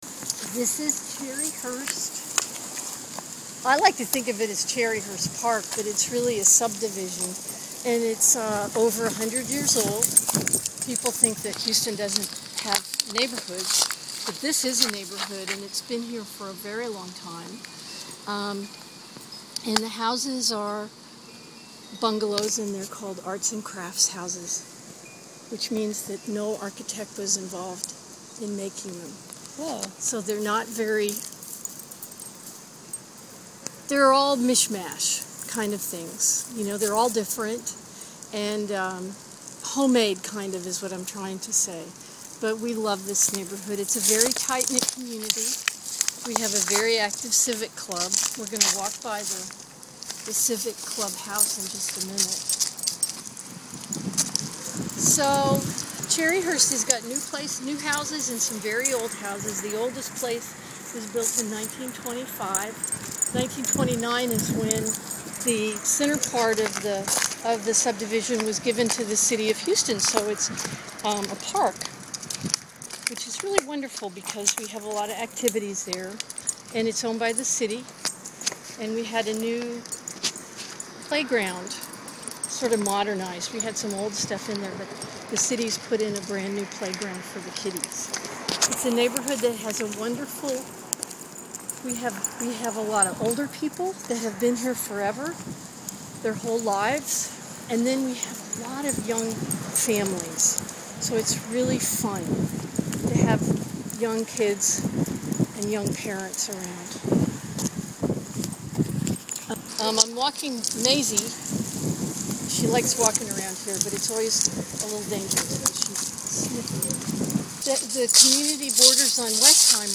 20 min 1 mile From California and Windsor to Dunlavy and Indiana To the sounds of a Saturday morning